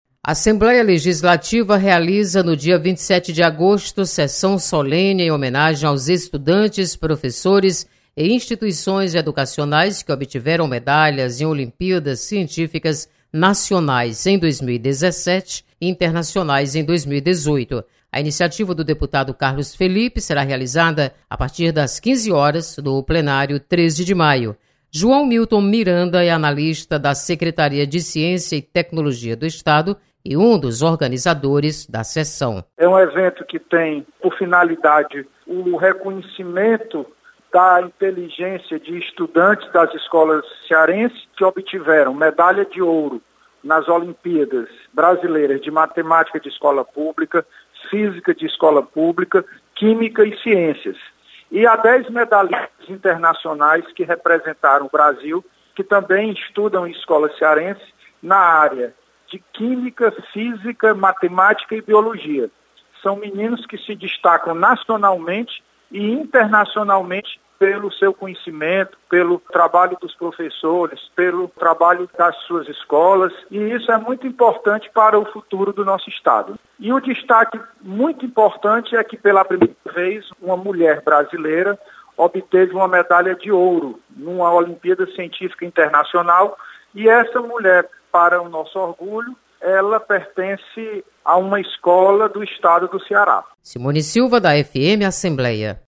Você está aqui: Início Comunicação Rádio FM Assembleia Notícias Sessão Solene